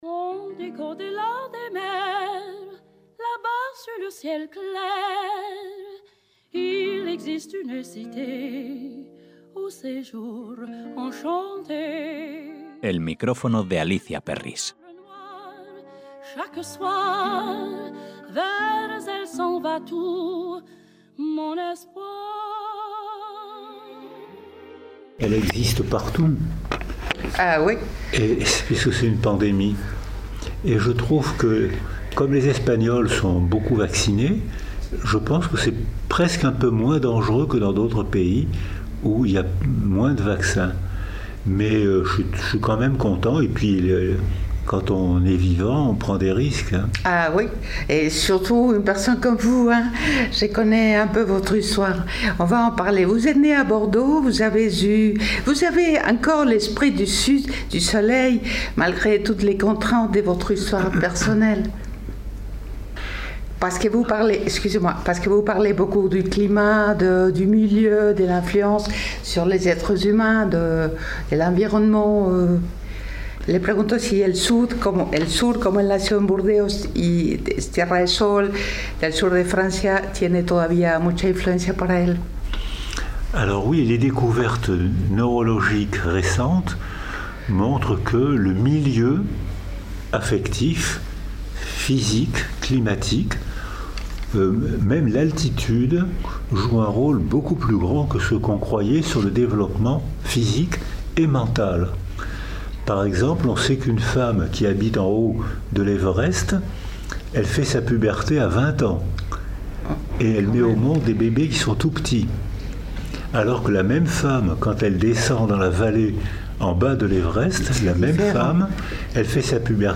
Entrevista con el psiquiatra francés y judío Boris Cyrulnik
La sesión en el Instituto Francés, coparticipada por Elsa Punset (cuyo padre era el televisivo comentador científico Eduardo Punset), con la sala llena, discurrió con humor y una visión divulgativa y “para todos los públicos” del pensamiento del escritor y médico francés.